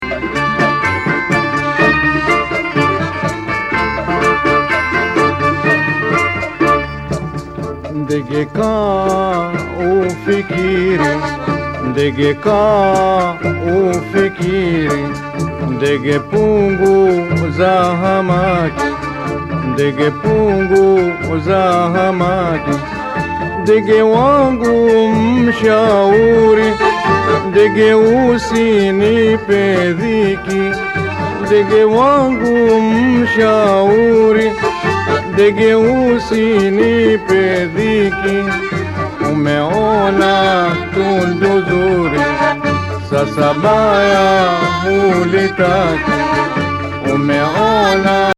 ターラブの中心地、ケニヤのモンバサで録音された楽曲を集めた編集盤!
日本から伝わったという、大正琴タシュコタがエレクトリック・シタールの